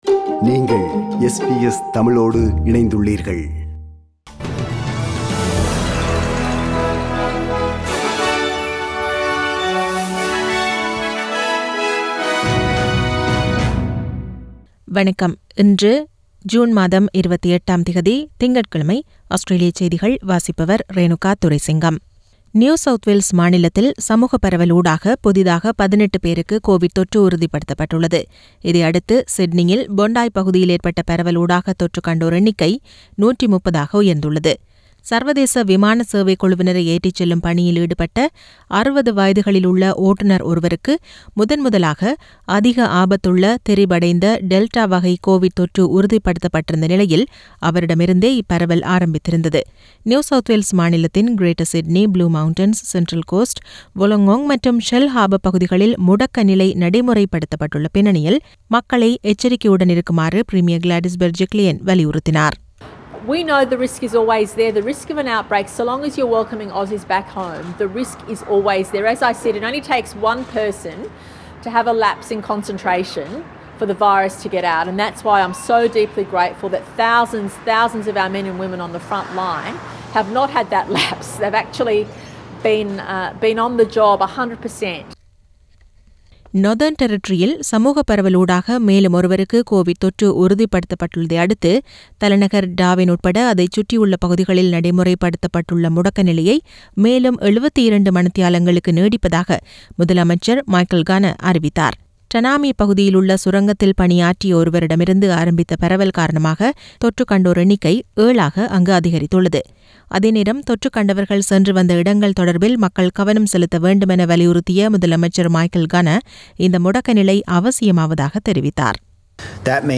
Australian news bulletin for Monday 28 June 2021.